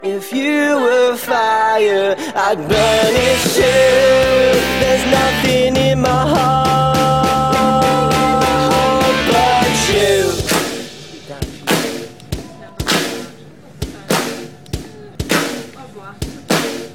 This is a sound sample from a commercial recording.
Reduced quality: Yes
It is of a lower quality than the original recording.